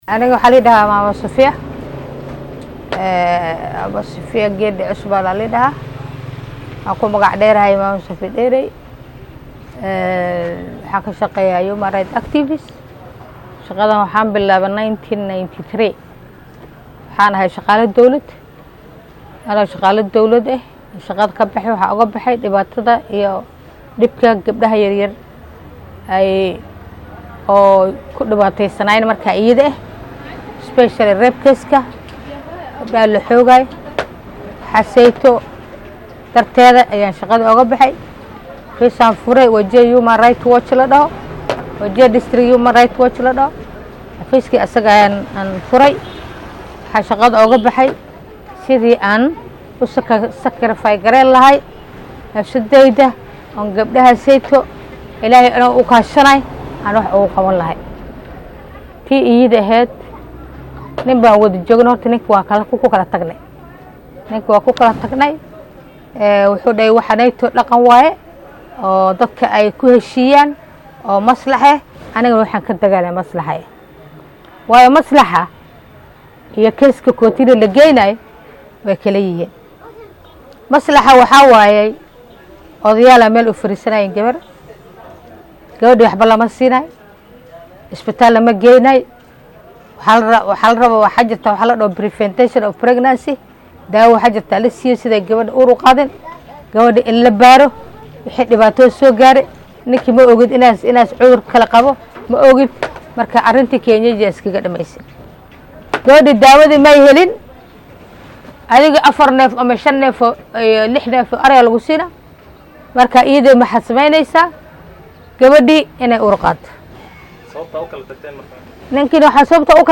DHAGEYSO:Haweeney u ololeysa xuquuqda gabdhaha Wajeer oo ka hadashay caqabadaha haysta